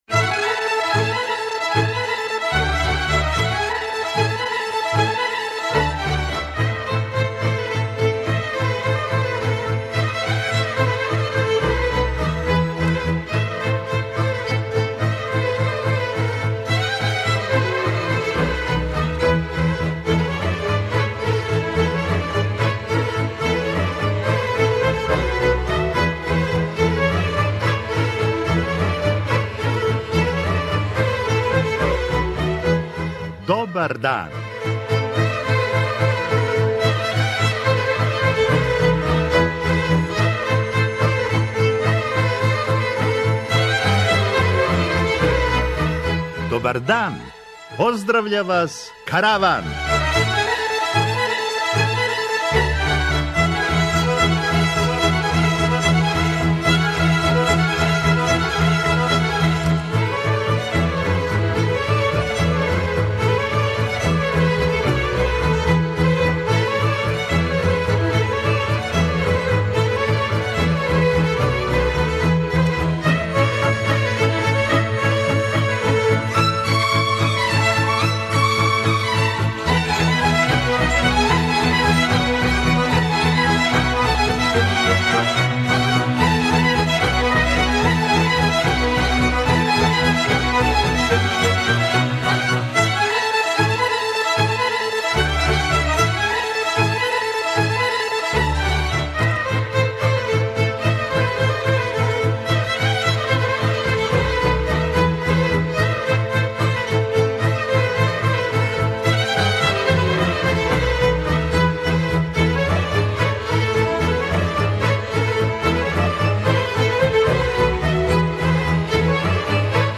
Сунчан дан помаже да будемо спортски расположени и зато ћемо емисију поделити с колегама из спортске редакције који су на стадионима по Србији и уживају ако не у фудбалу, онда свакако у сунчању.